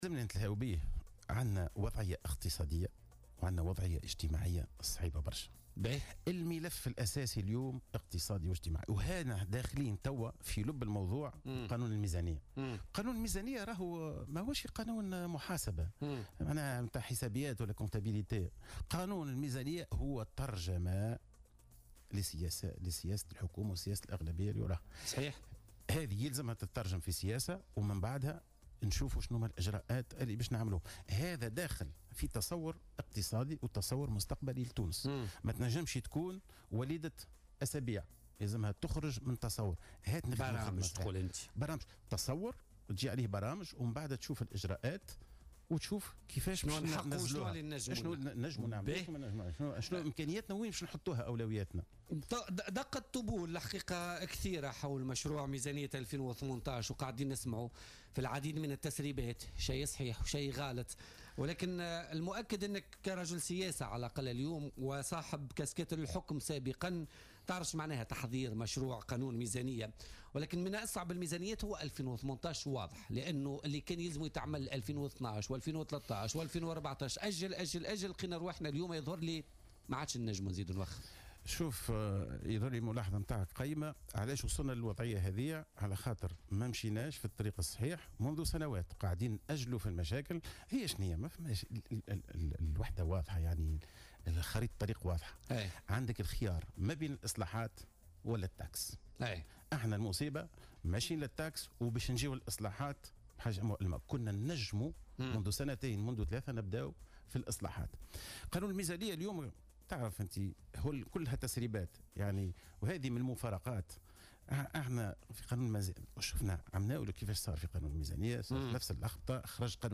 Le fondateur et président d'Al Badil Ettounsi, Mehdi Jomâa, est revenu, dans une déclaration à Jawhara Fm, ce mercredi 11 octobre 2017, sur la situation économique du pays et sur la Loi de finances 2018 qui fait actuellement polémique. Mehdi Jomâa considère qu’il faudrait une Loi de finances courageuse qui entame les réformes nécessaires.